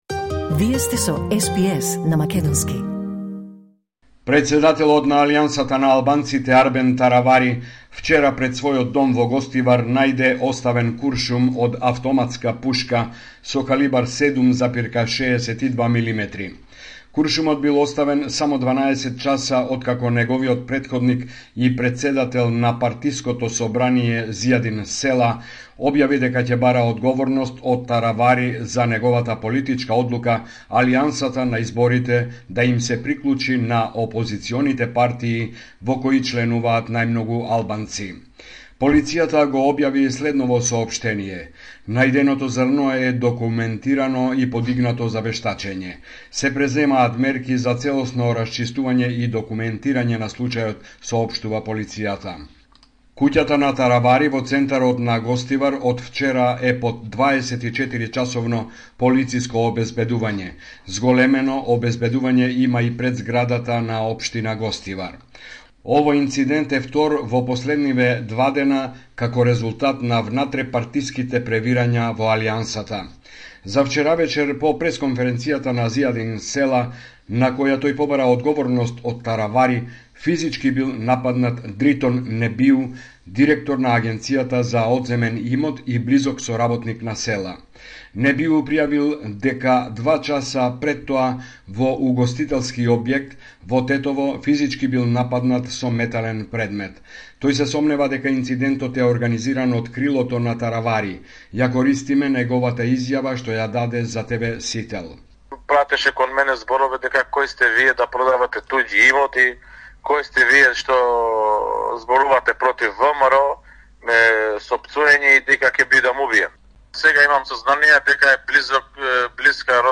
Homeland Report in Macedonian 6 February 2024